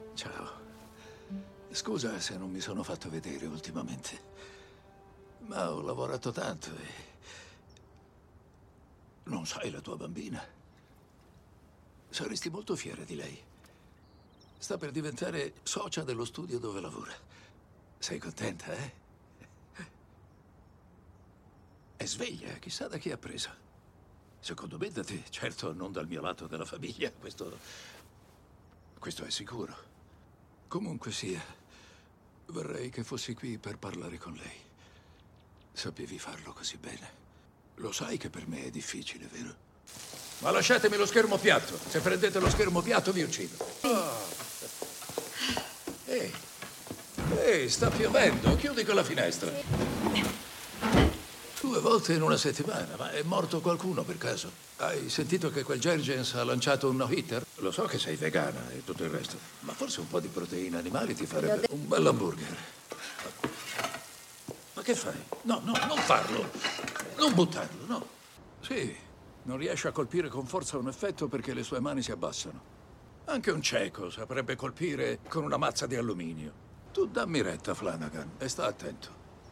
voce di Michele Kalamera nel film "Di nuovo in gioco", in cui doppia Clint Eastwood.